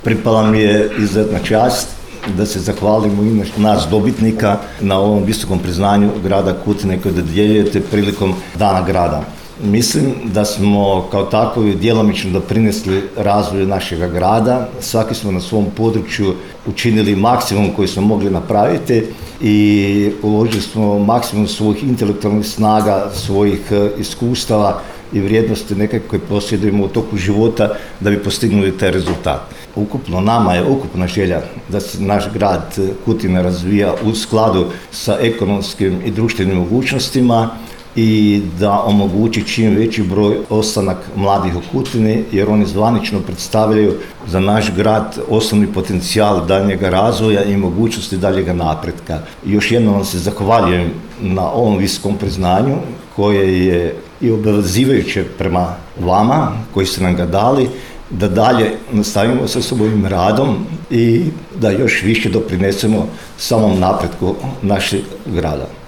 U povodu proslave Dana grada Kutine i obilježavanja Petrova u petak, 27. lipnja 2025. godine, održana je svečana sjednica Gradskog vijeća Grada Kutine.